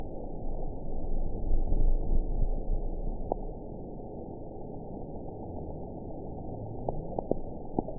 event 920789 date 04/09/24 time 06:15:34 GMT (1 year, 2 months ago) score 5.44 location TSS-AB05 detected by nrw target species NRW annotations +NRW Spectrogram: Frequency (kHz) vs. Time (s) audio not available .wav